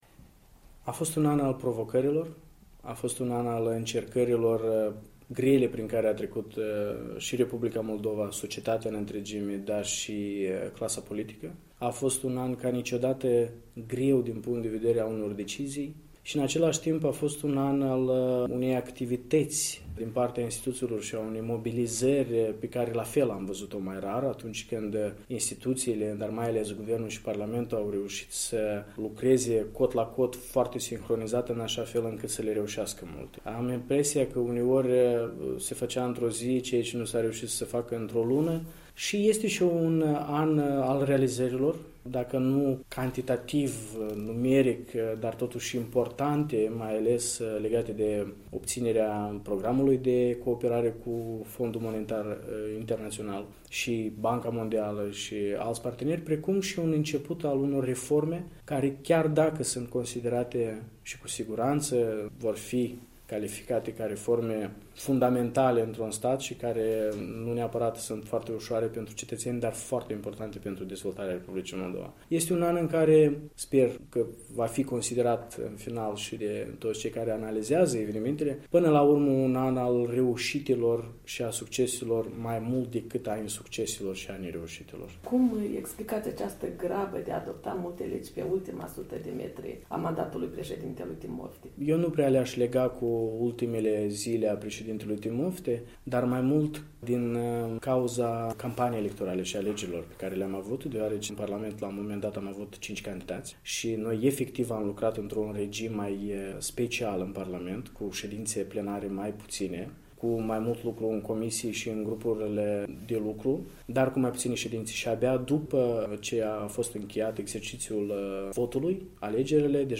În dialog cu Andrian Candu